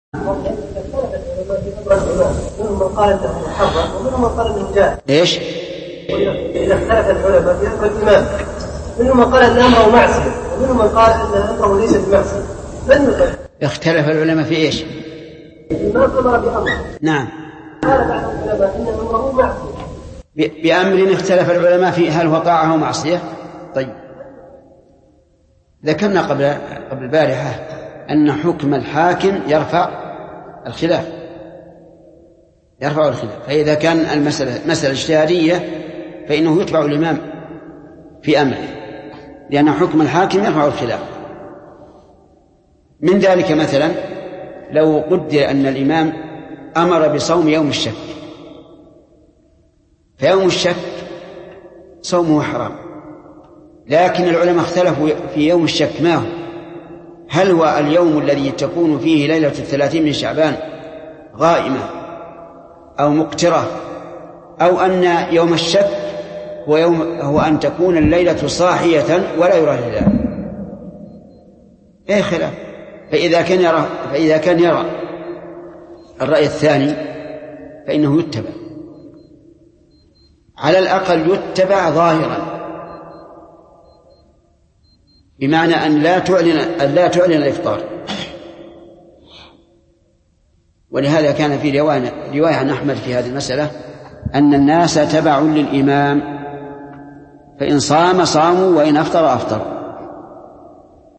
Album: موقع النهج الواضح Length: 1:41 minutes (473.49 KB) Format: MP3 Mono 22kHz 32Kbps (VBR)